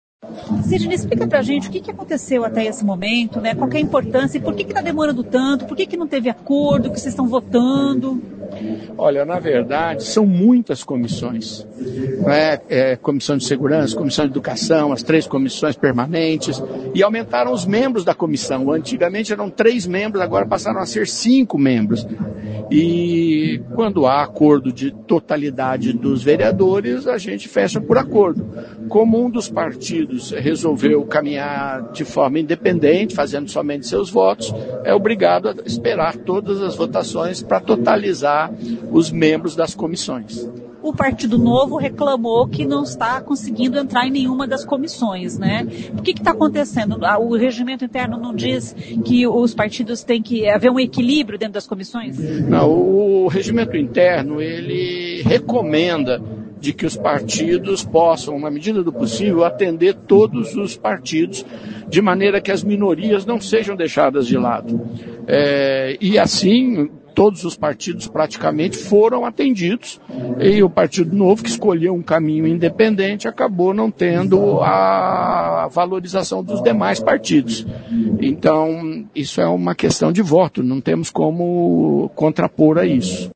E a sessão foi mais longa do que em anos anteriores porque o número de integrantes das comissões aumentou para cinco, explicou o vereador Sidnei Telles.